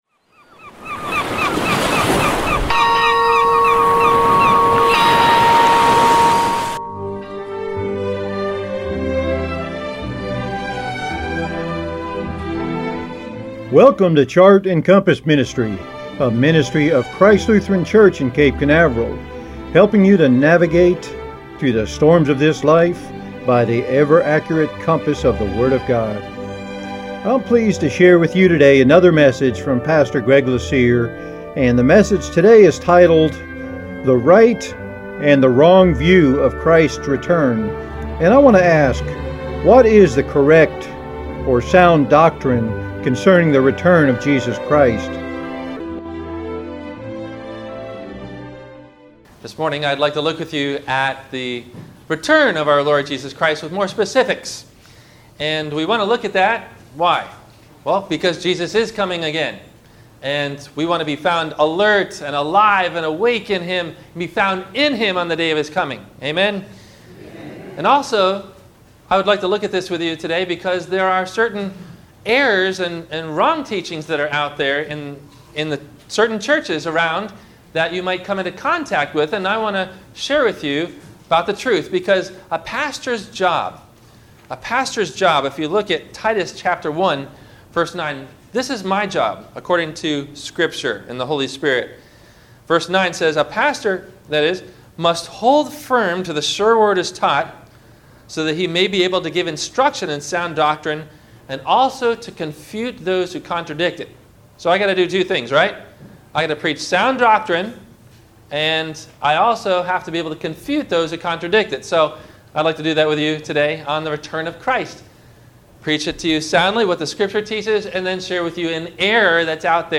Have a Comment or Question about the Sermon?